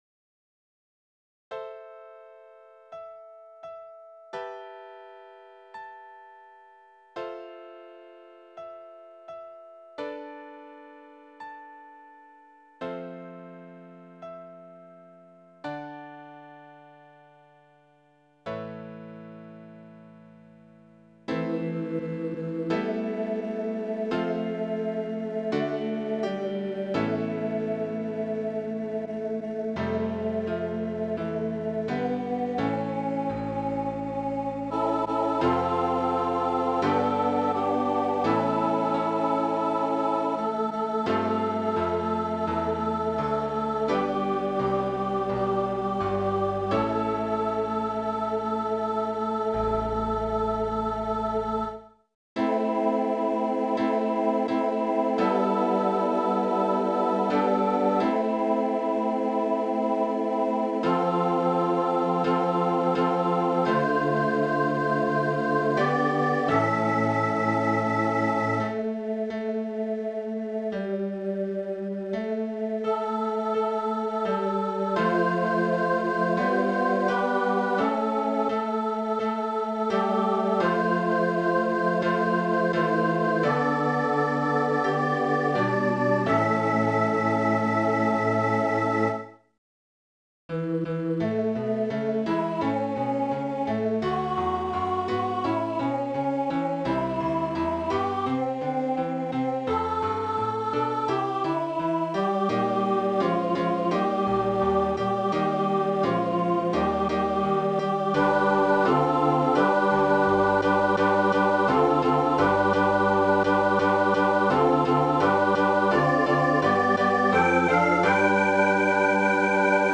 In the Beginning, SATB with Tenor Solo
Voicing/Instrumentation: SATB
Choir with Soloist or Optional Soloist